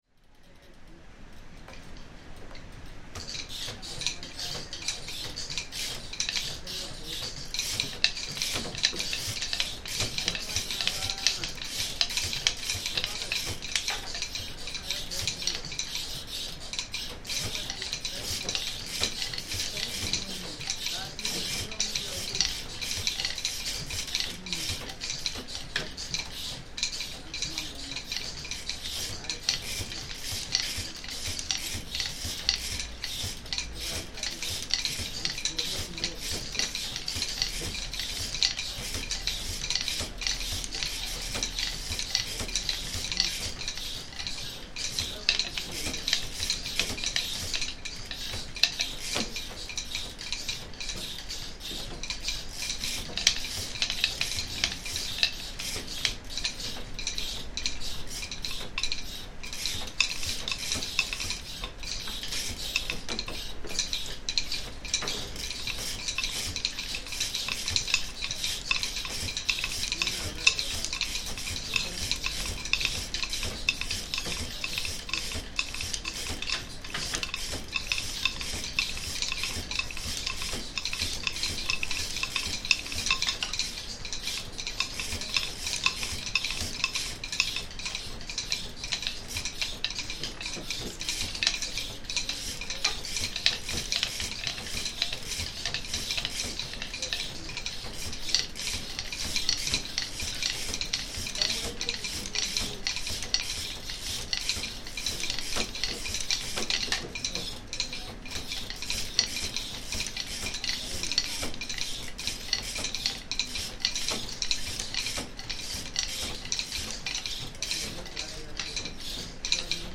Asante Kente weaving